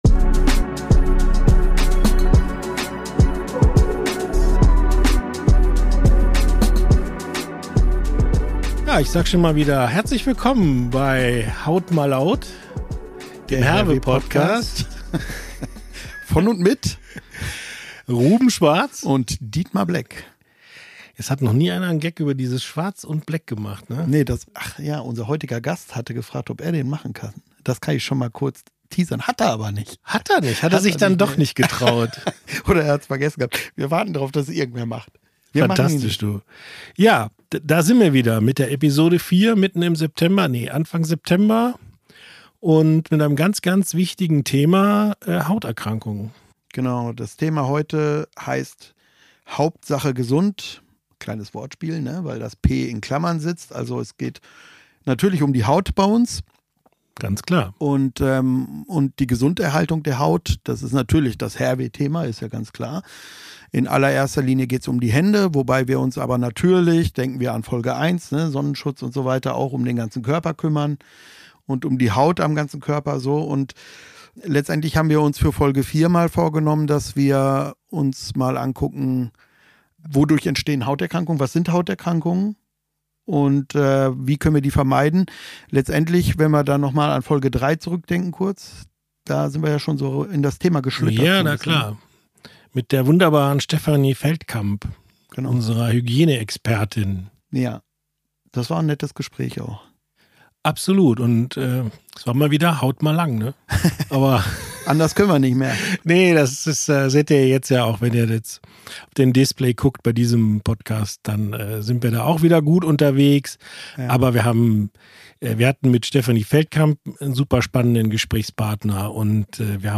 Ein entspannter Talk über ein Thema, das uns alle betrifft – die Gesundheit der Haut!